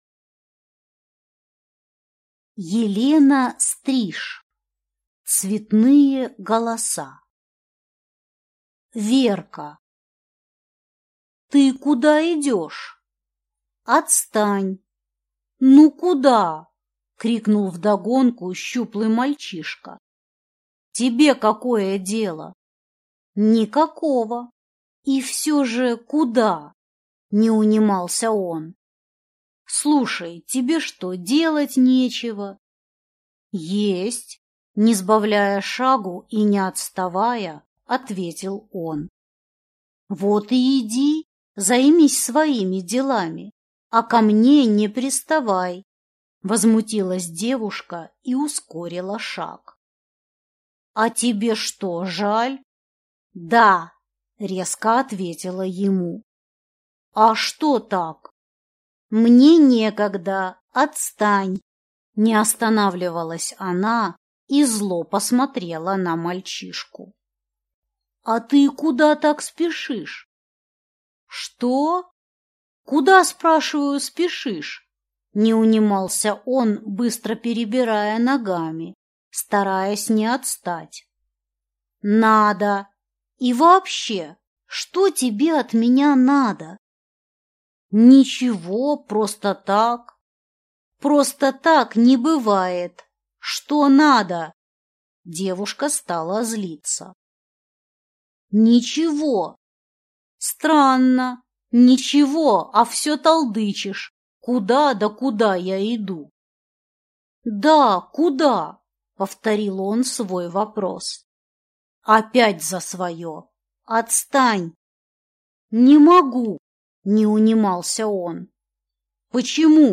Аудиокнига Цветные голоса | Библиотека аудиокниг